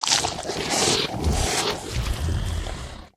fracture_eat_3.ogg